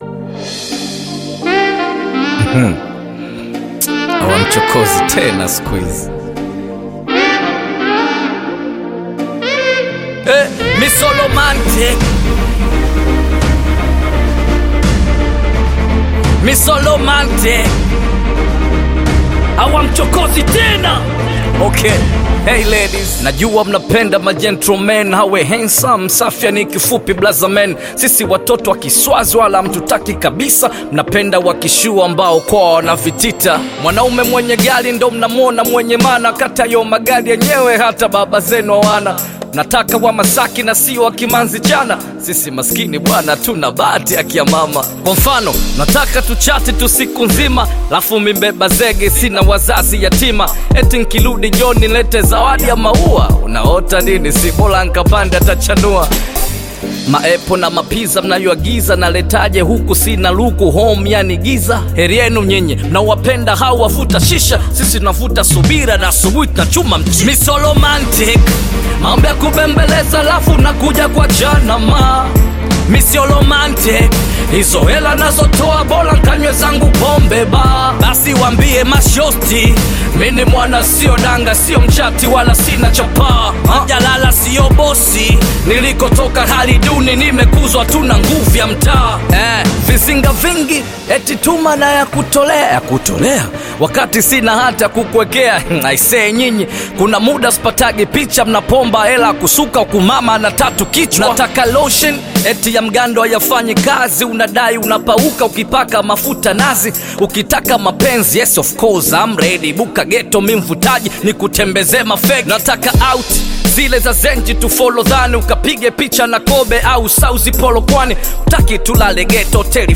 Bongo Flava
Bongo Flava You may also like